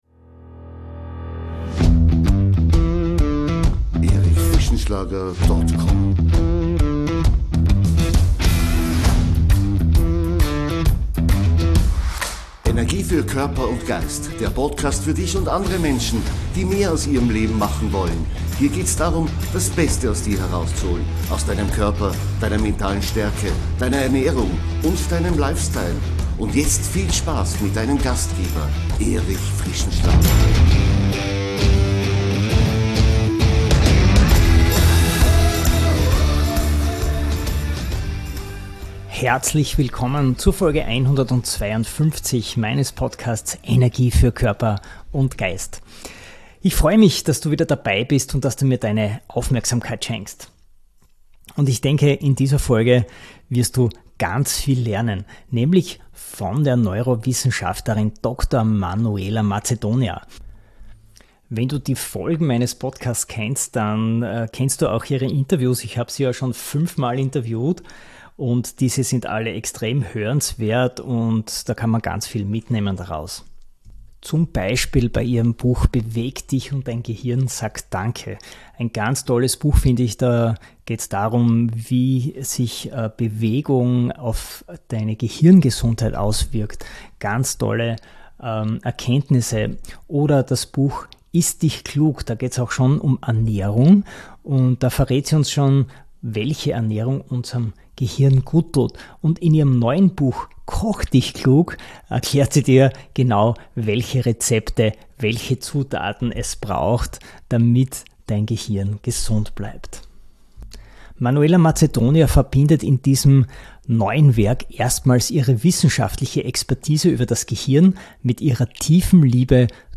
Freu dich auf ein Gespräch voller inspirierender Gedanken, praktischer Tipps und faszinierender Einblicke in die Funktionsweise unseres erstaunlichsten Organs. Und natürlich darauf, wie genussvolles Essen zu einem echten Brain-Boost werden kann – für mehr Wohlbefinden, Klarheit und Vitalität bis ins hohe Alter.